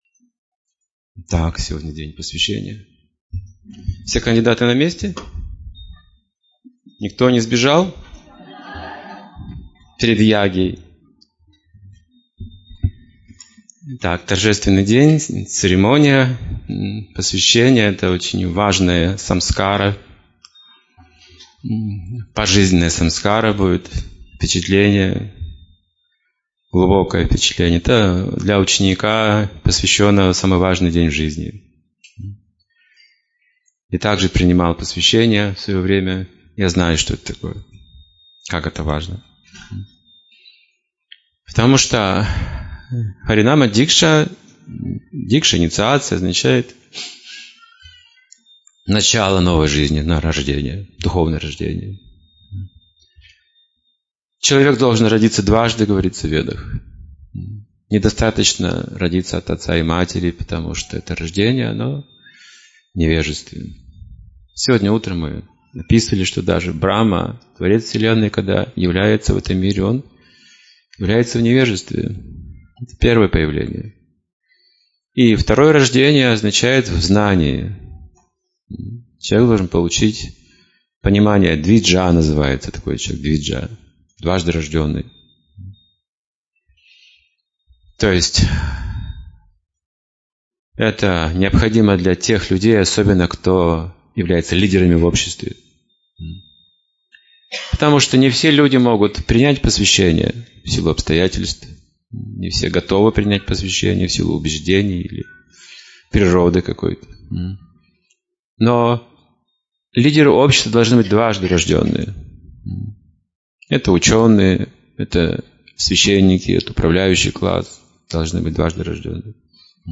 Лекция на инициации (2016, Омск)